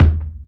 Kick Kitchen.wav